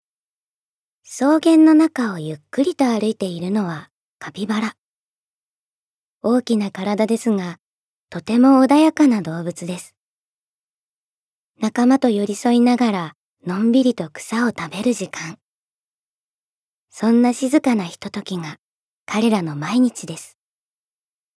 ボイスサンプル
セリフ５